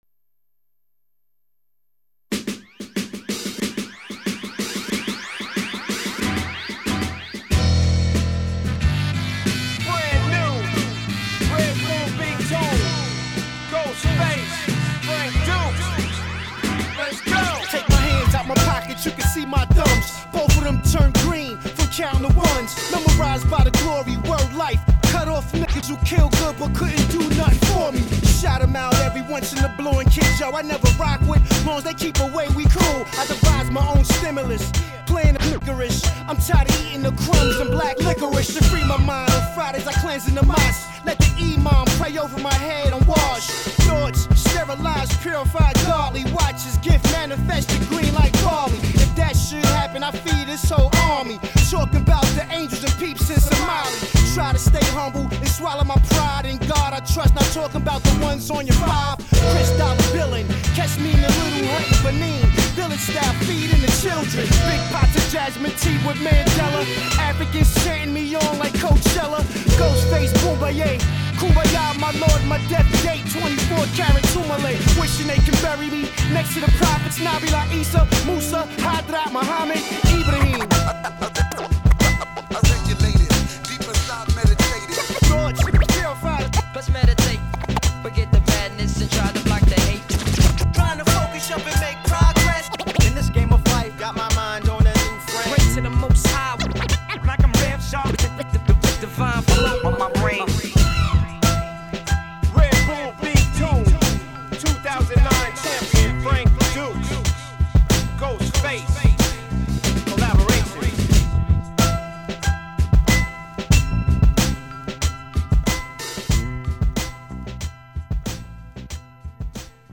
hip hop beats